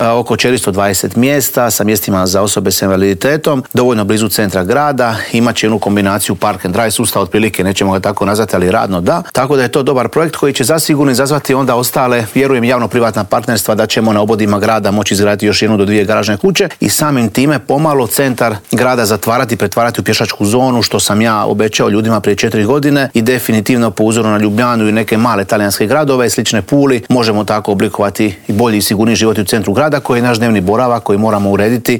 ZAGREB - Po novi četverogodišnji mandat na lokalnim izborima u Puli ide aktualni gradonačelnik Filip Zoričić.